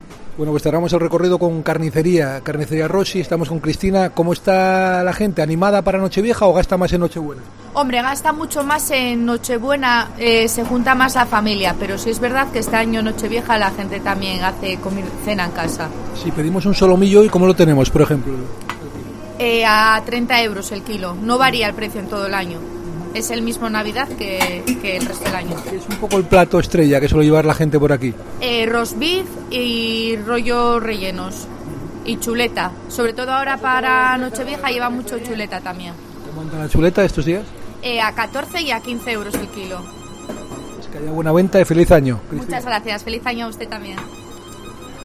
Hemos estado en el tradicional Mercado del Sur de Gijón y te acercamos los precios de los manjares tradicionales para la última cena del año, con el postre estrella incluido